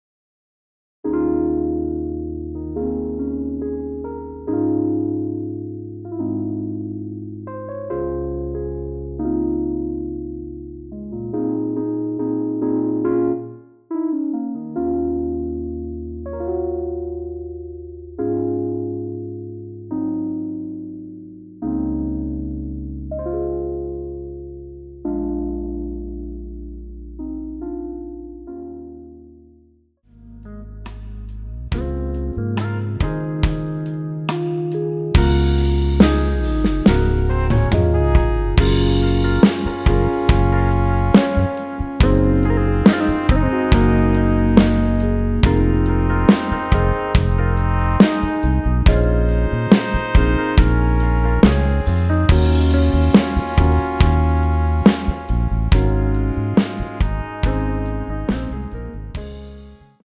C#
앞부분30초, 뒷부분30초씩 편집해서 올려 드리고 있습니다.
중간에 음이 끈어지고 다시 나오는 이유는